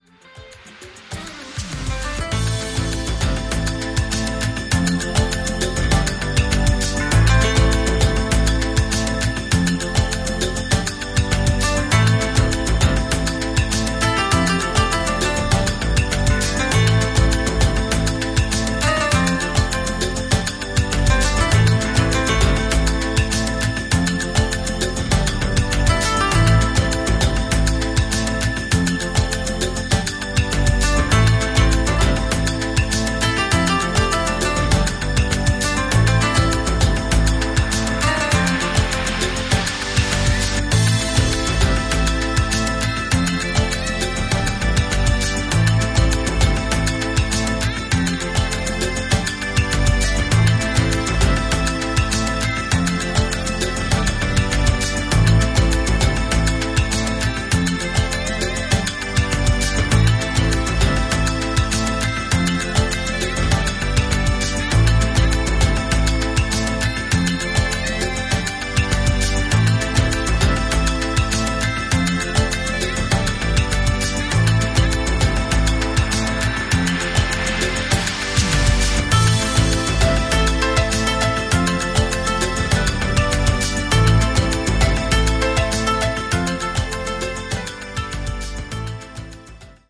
Balearic